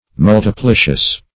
Multiplicious \Mul`ti*pli"cious\, a.
multiplicious.mp3